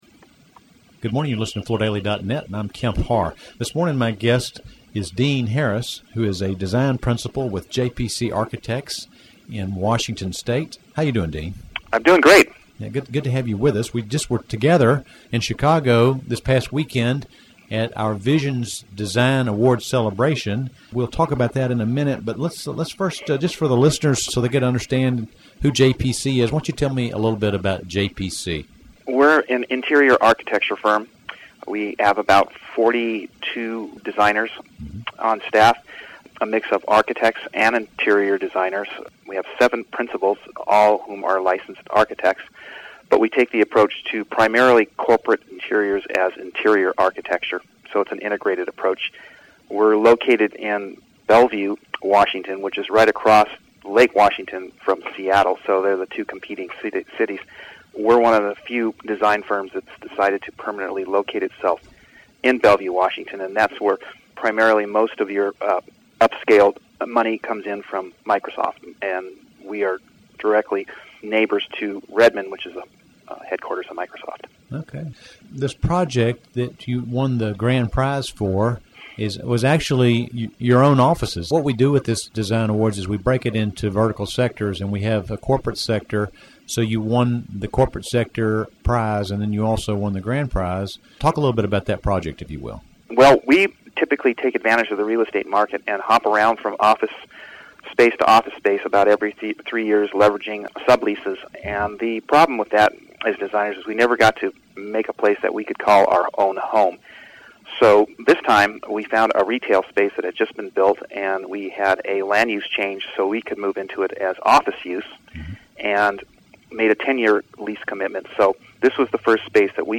Listen to the interview to hear more about JPC, their winning entry, the Vision Design Awards and about current business conditions for this design firm.